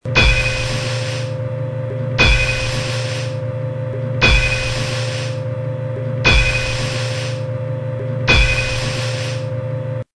pump_room.mp3